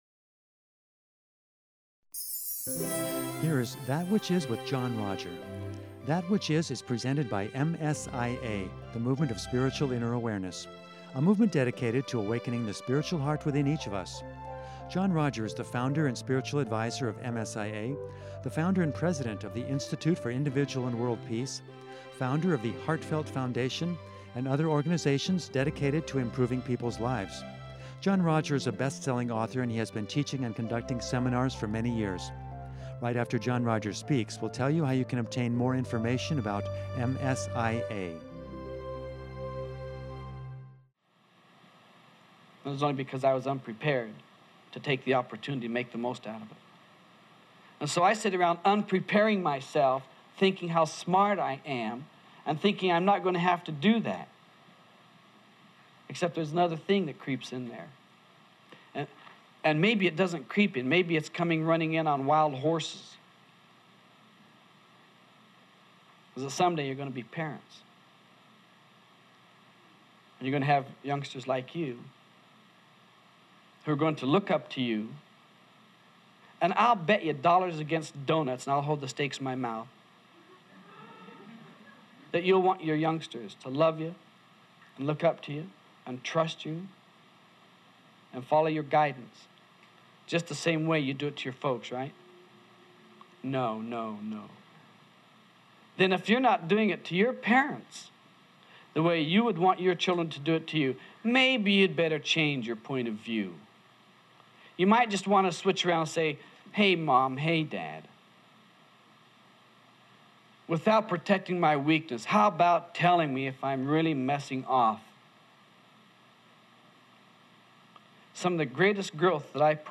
In the second part of this seminar